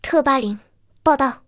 T-80编入语音.OGG